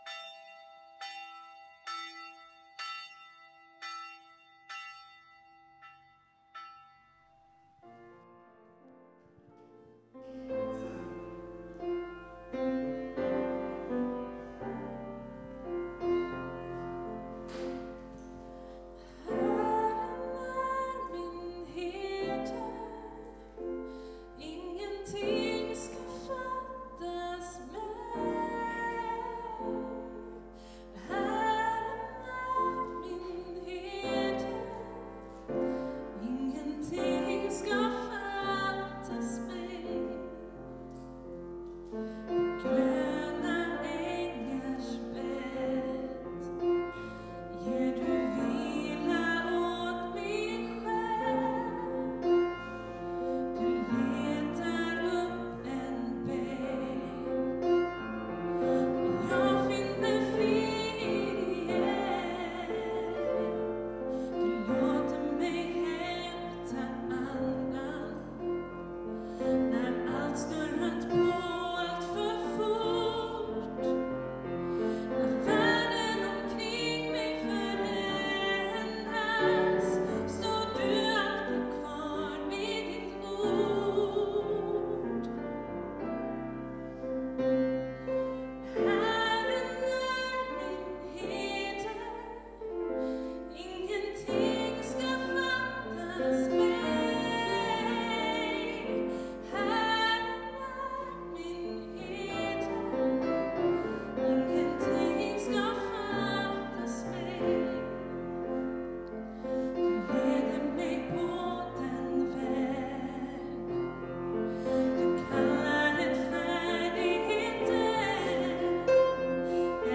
Gudstjänst från Älvsjökyrkan
Sång
Piano